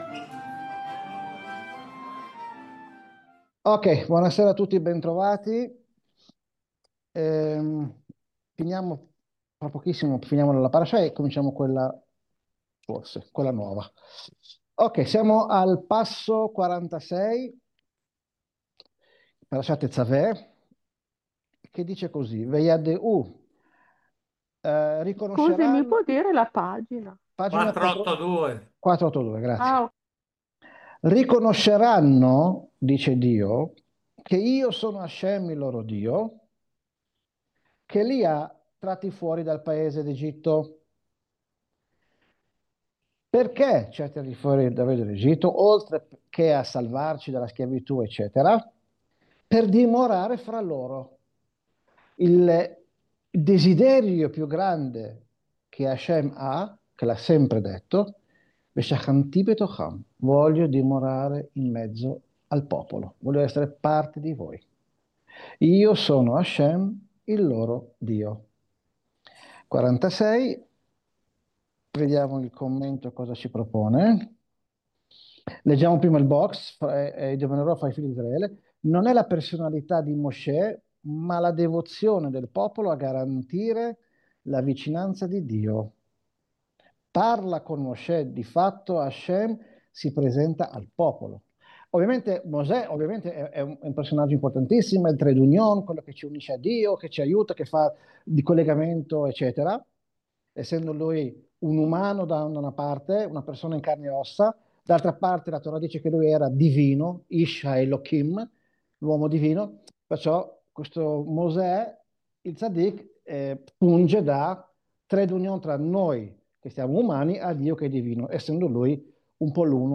Lezione del 5 novembre 2025